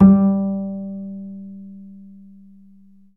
DBL BASS AN3.wav